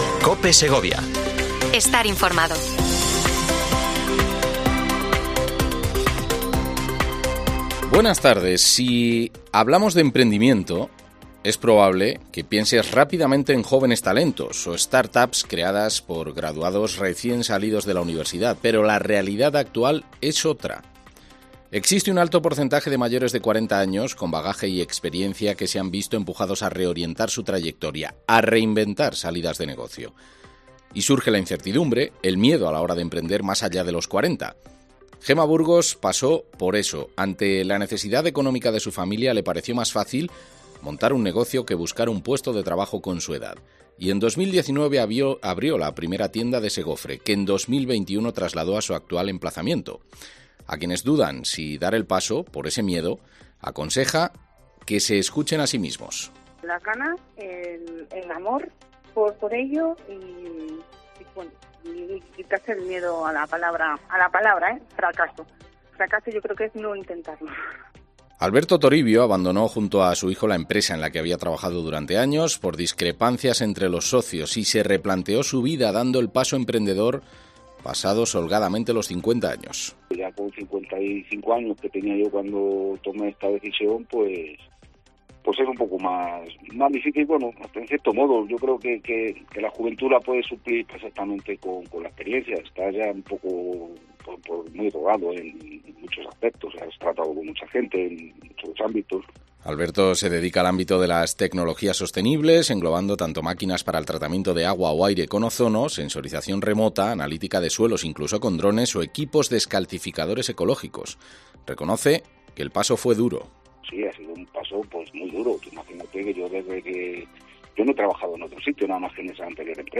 Informativo local Mediodía Cope Segovia, 14:20h. 18 de abril